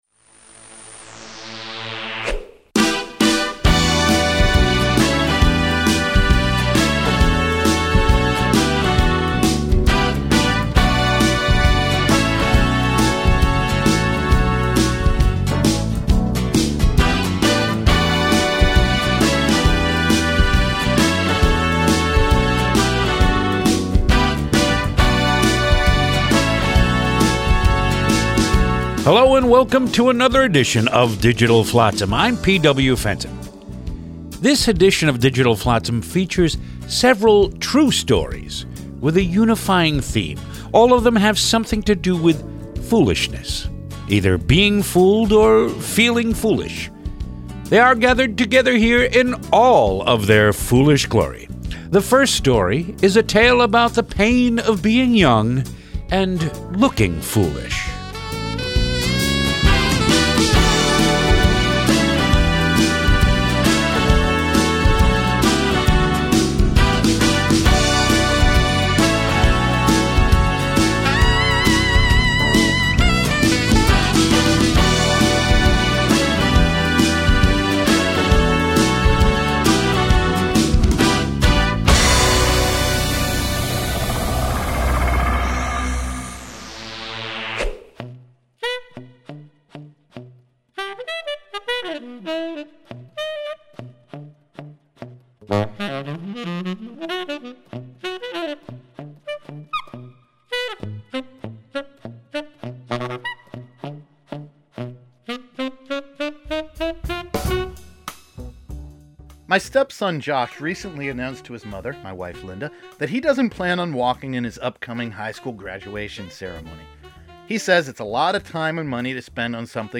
We are proud to offer these great spoken word pieces again.